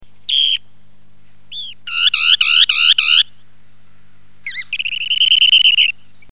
Bécasseau  variable
Calidris alpina
becasseau_v.mp3